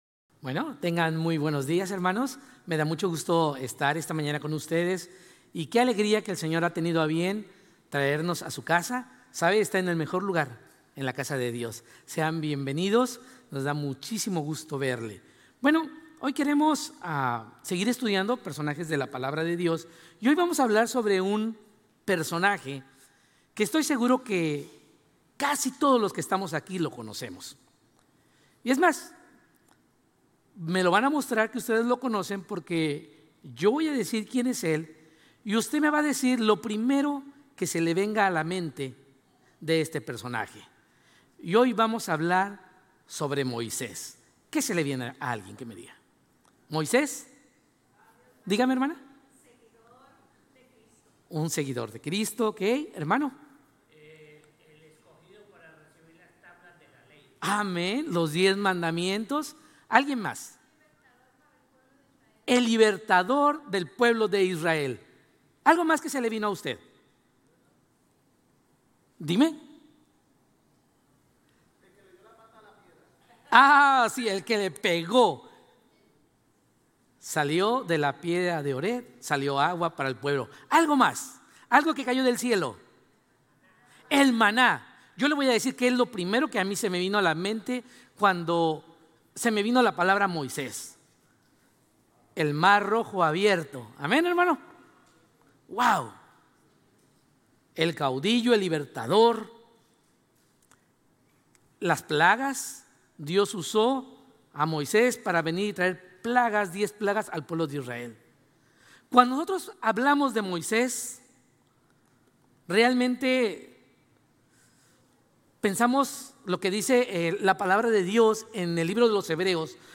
Sermones Grace Español 7_6 Grace Espanol Campus Jul 06 2025 | 00:38:36 1x 00:00 / 00:38:36 Subscribe Share RSS Feed Share Link Embed